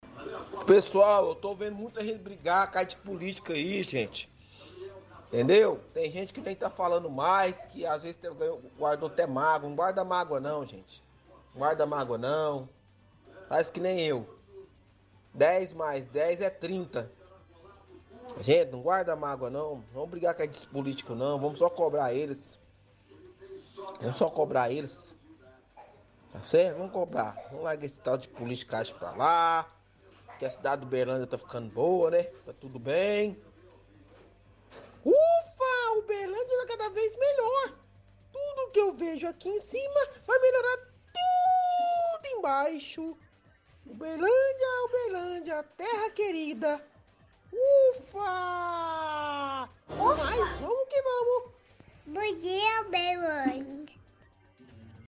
– Ouvinte pede que outros ouvintes não briguem por política, ironizando que a cidade de Uberlândia está boa e debochando ao imitar o drone da prefeitura.